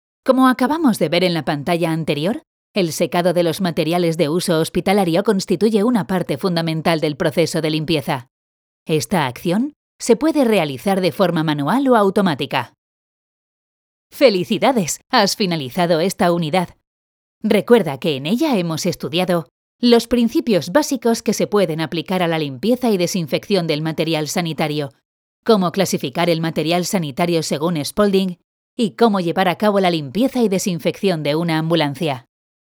Locutora española 11 años experticia con home studio, disponibilidad, flexibilidad horarios y festivos y rapidez.
kastilisch
Sprechprobe: eLearning (Muttersprache):
Voice over freelance with home studio pro.